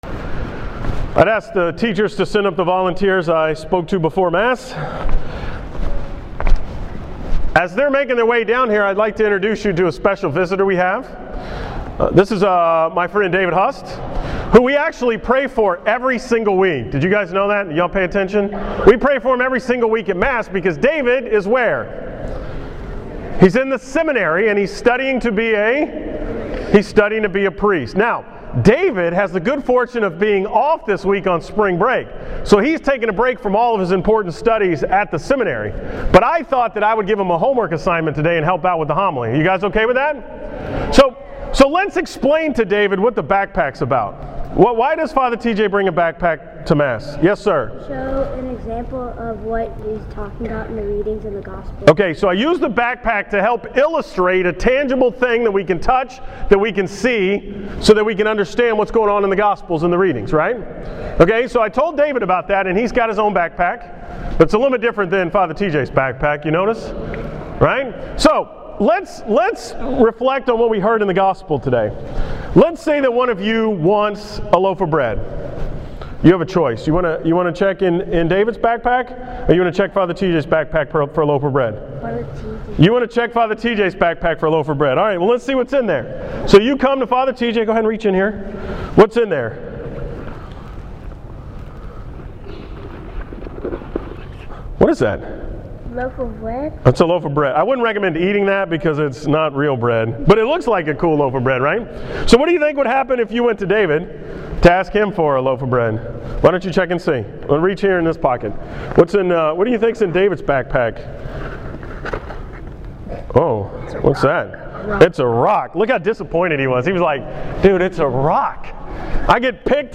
From the school Mass on Thursday, March 13th
Category: 2014 Homilies, School Mass homilies